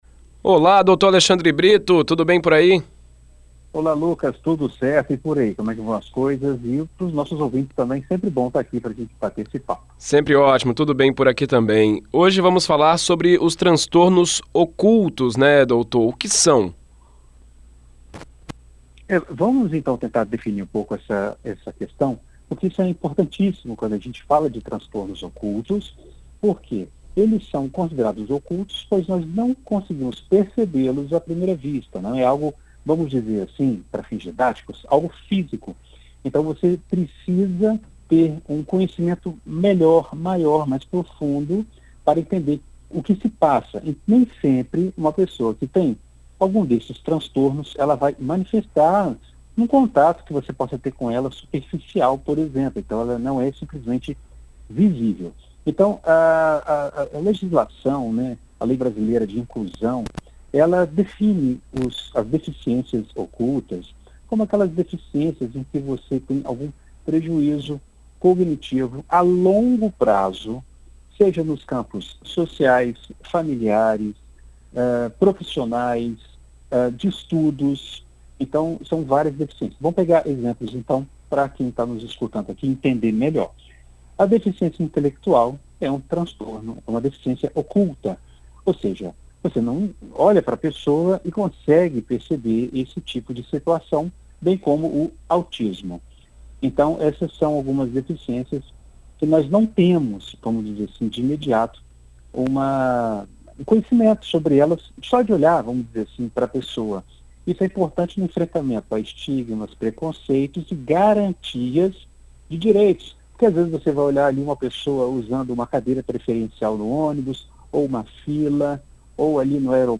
Na coluna Psicologia e Vida Cotidiana desta segunda-feira (05), na BandNews FM ES